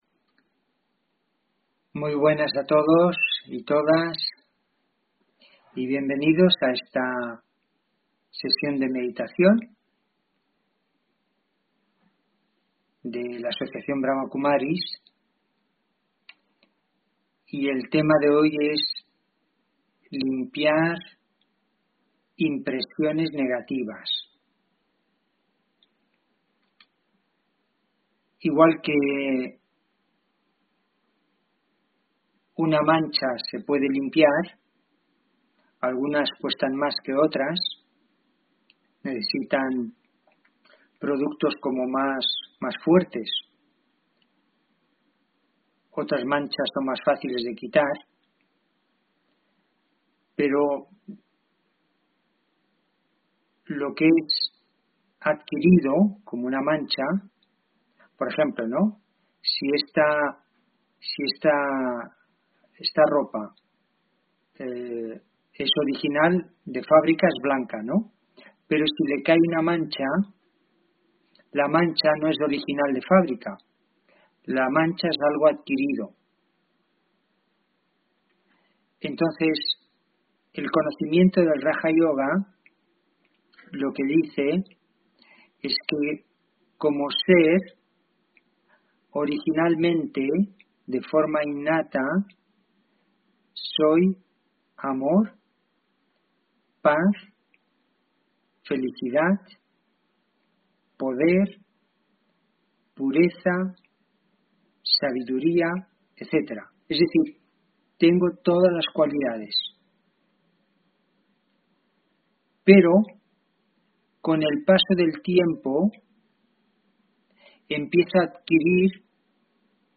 Meditación Raja Yoga y charla: Meditar para vivir con amor y desapego (2 Diciembre 2020) ...